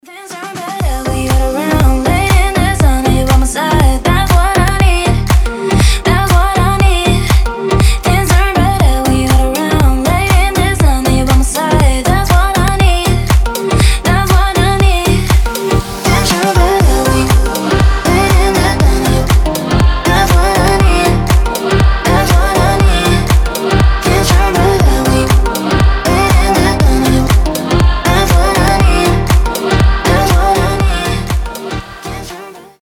• Качество: 320, Stereo
мелодичные
заводные
женский голос
Dance Pop
tropical house
теплые
Заводная мелодия на звонок твоего телефона